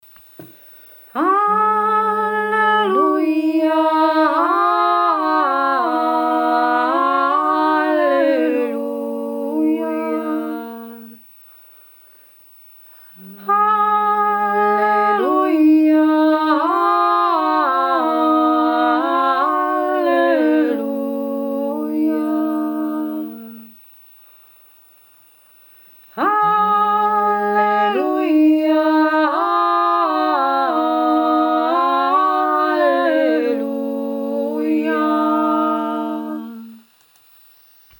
Es ist ein eher archaischer Gesang, vielleicht mehr ein Ruf als ein Lied:
HALLELUJA 2 Stimme